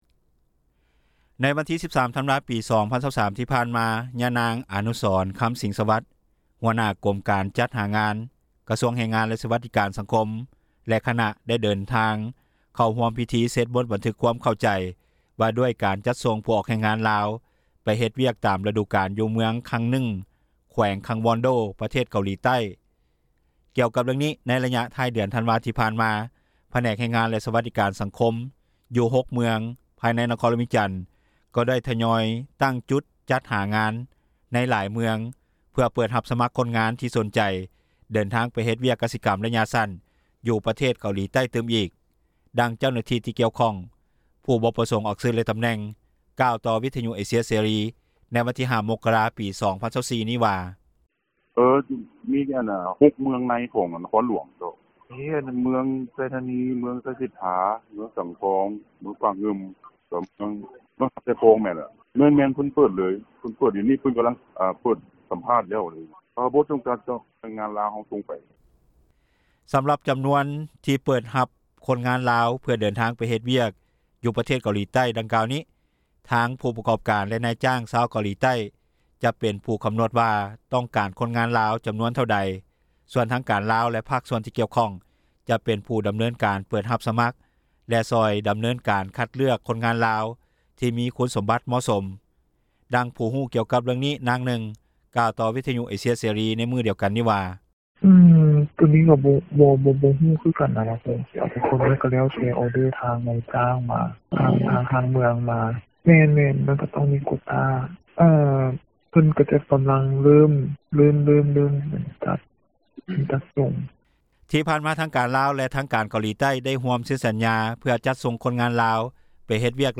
ດັ່ງຜູ້ທີ່ຮູ້ກ່ຽວກັບເຣື່ອງນີ້ນາງນຶ່ງ ກ່າວຕໍ່ວິທຍຸເອເຊັຽເສຣີ ໃນມື້ດຽວກັນນີ້ວ່າ:
ດັ່ງຊາວນະຄອນຫຼວງວຽງຈັນ ທີ່ຕ້ອງການເດີນທາງ ໄປເຮັດວຽກຢູ່ເກົາຫຼີໃຕ້ກ່າວວ່າ: